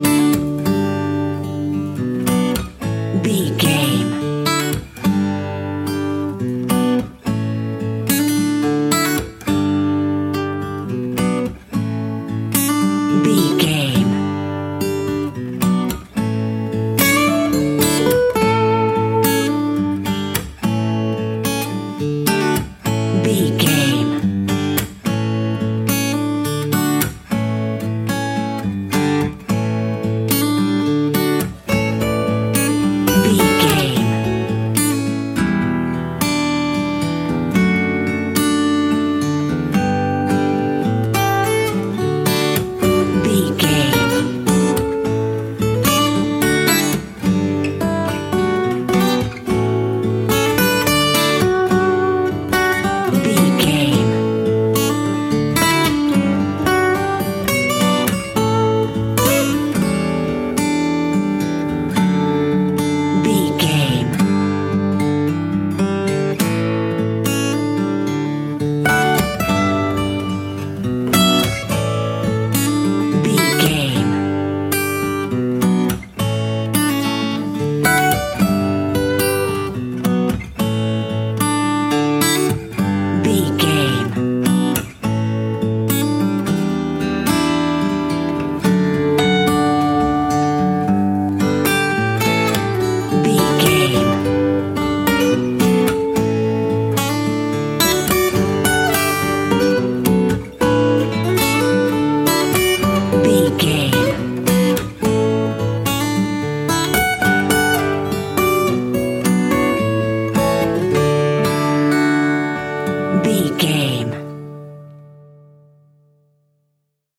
Ionian/Major
D
acoustic guitar
Pop Country
country rock
bluegrass
happy
uplifting
driving
high energy